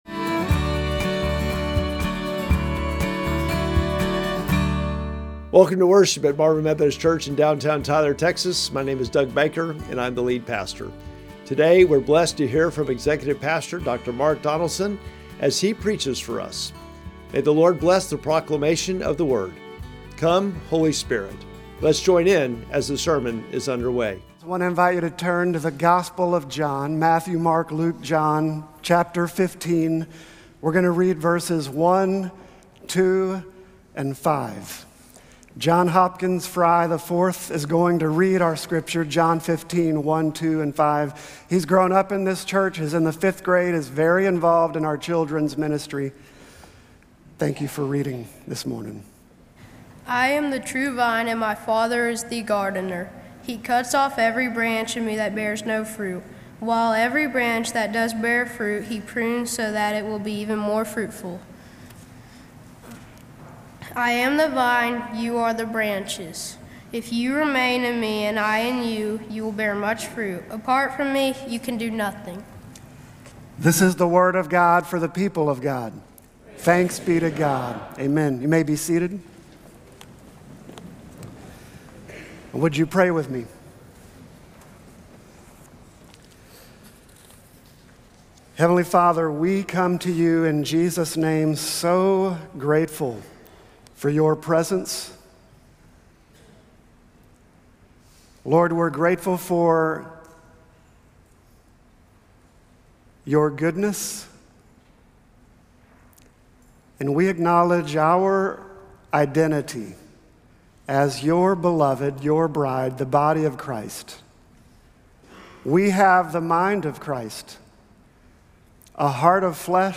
Sermon text: John 15:1-2, 5